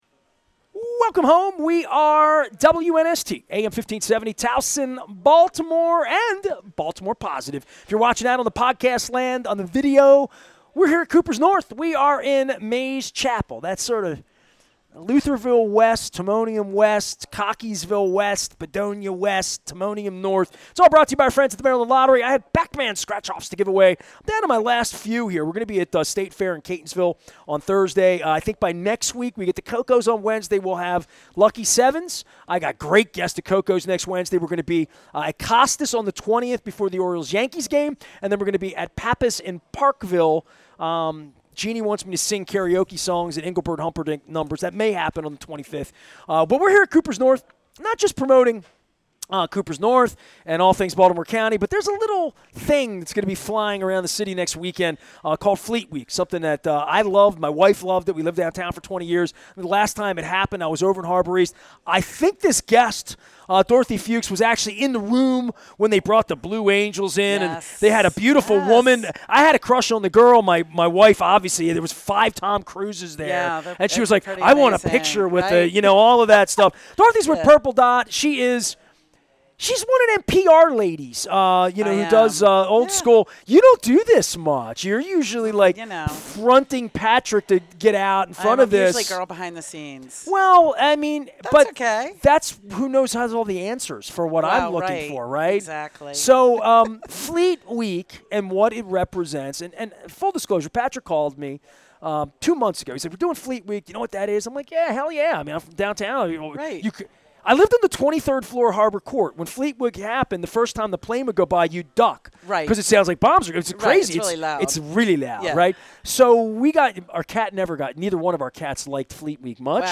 at Kooper's North on the Maryland Crab Cake Tour